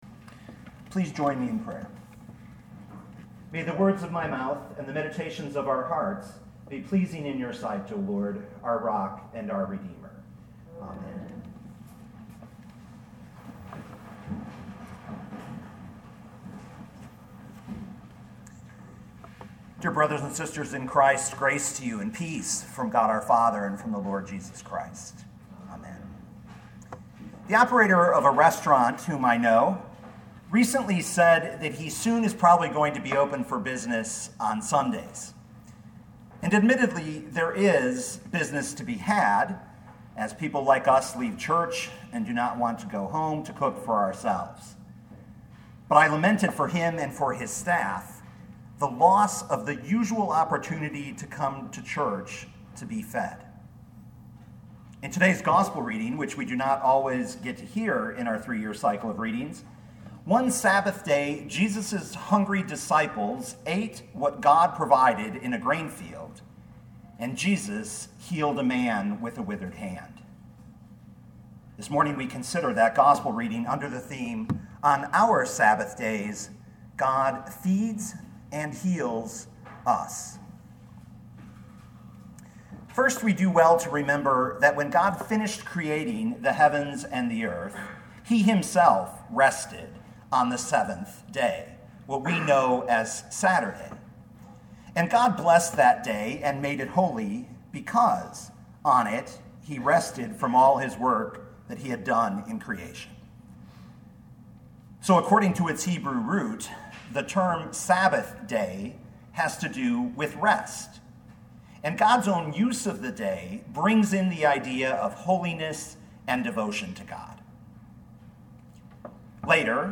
2018 Mark 2:23-3:6 Listen to the sermon with the player below, or, download the audio.